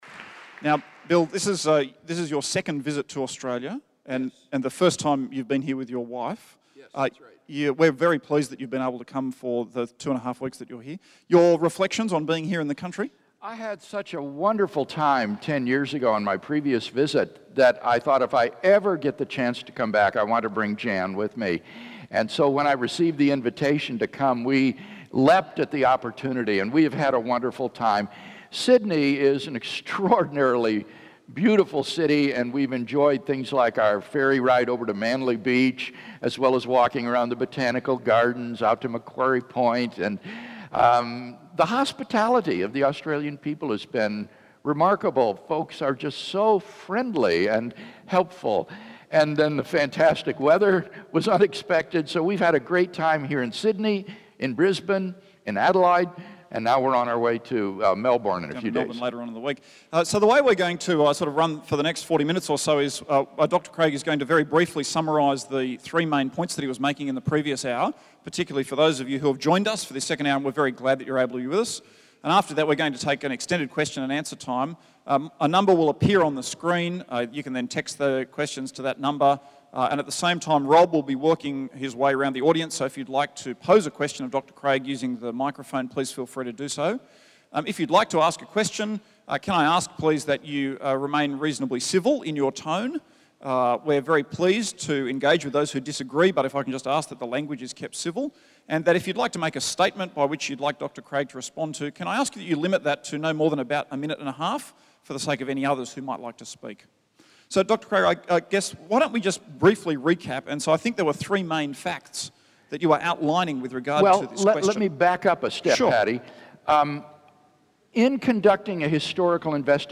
Evidence For the Resurrection (Part 1) Author: William Lane Craig Date: Sunday, 11 August 2013 Tags: Resurrection Description: William Lane Craig spoke to a full house in The Great Hall, University of Sydney, on the topic of Evidence for the Resurrection.